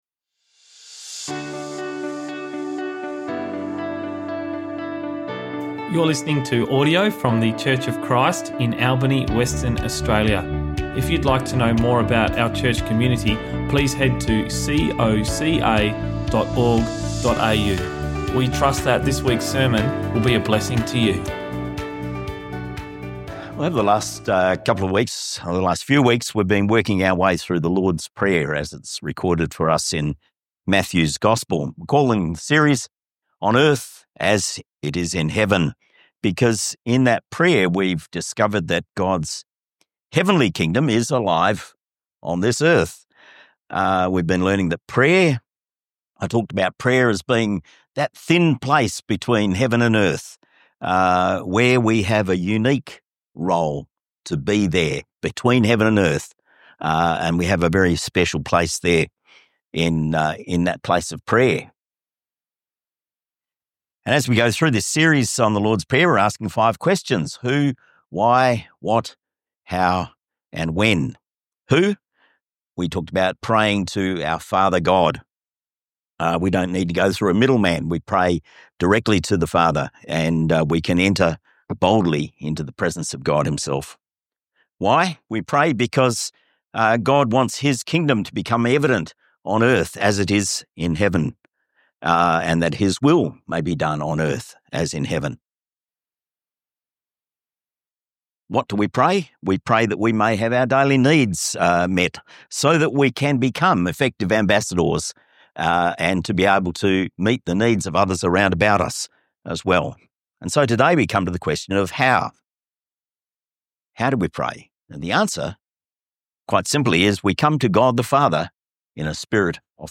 Sermons | Church of Christ Albany